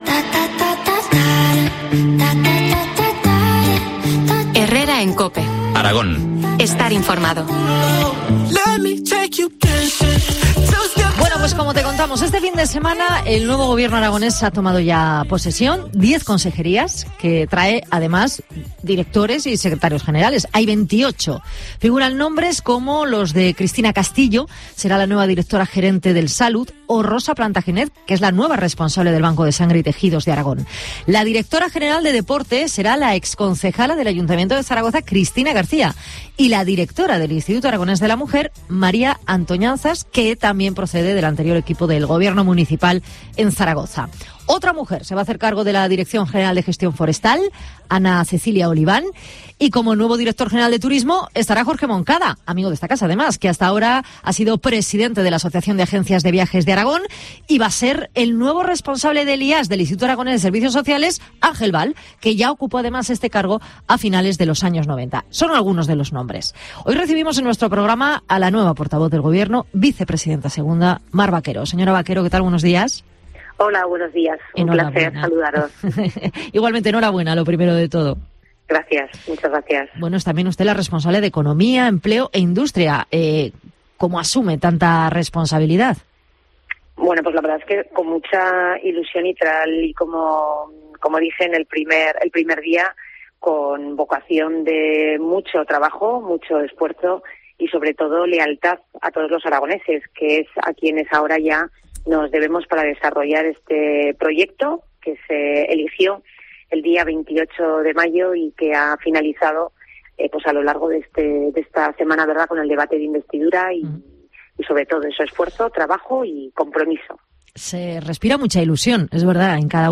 Entrevista a la portavoz y vicepresidenta segunda del Gobierno de Aragón, Mar Vaquero.